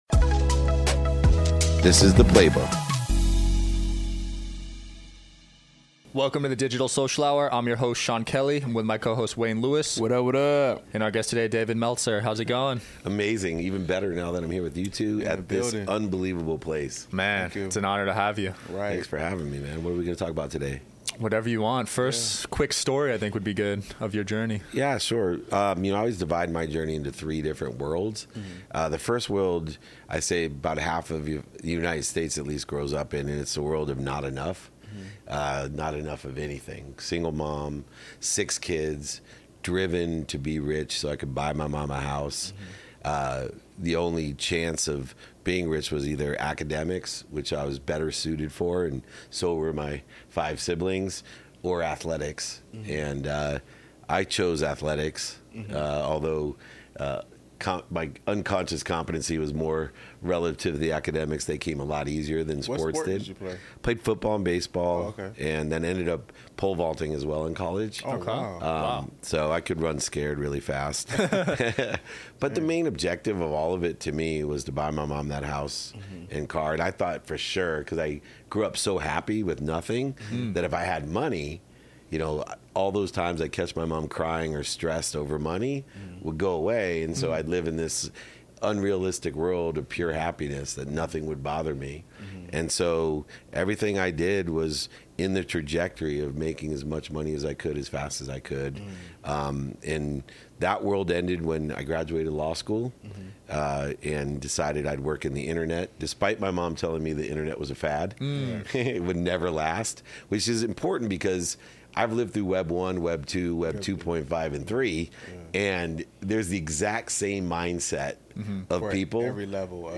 Today’s episode is an interview